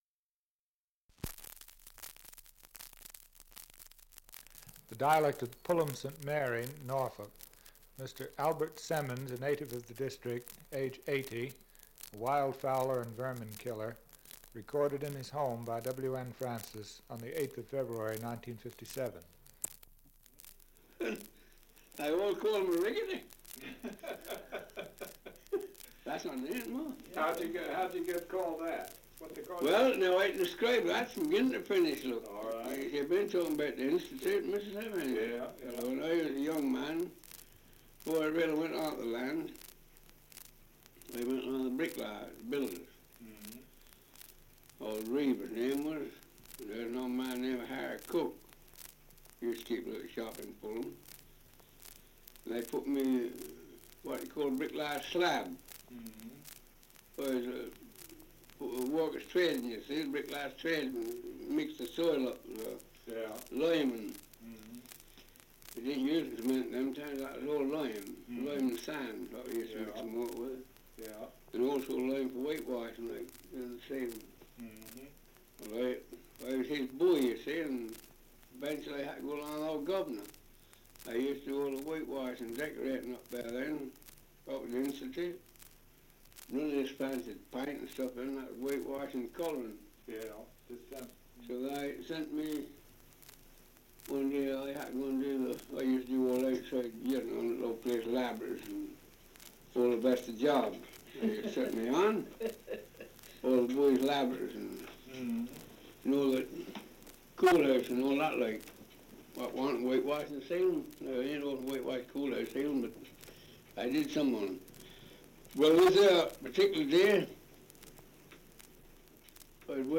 Survey of English Dialects recording in Pulham St Mary, Norfolk
78 r.p.m., cellulose nitrate on aluminium